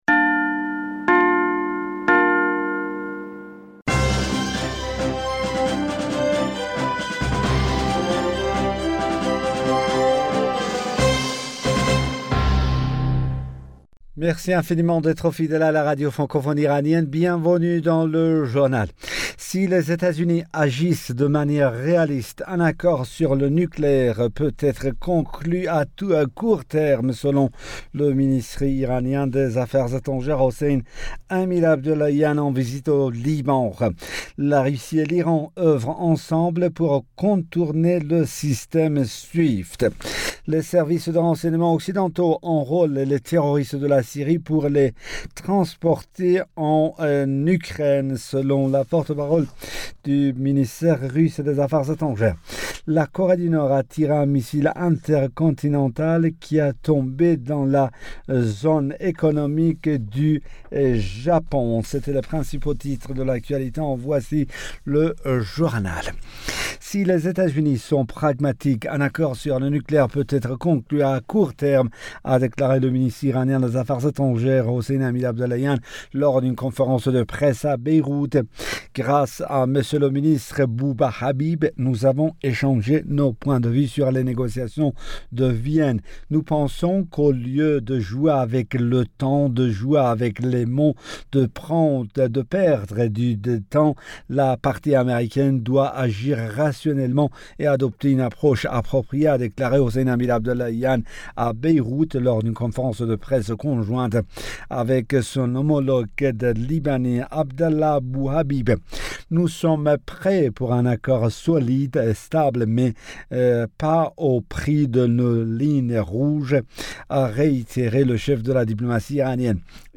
Bulletin d'information Du 25 Mars 2022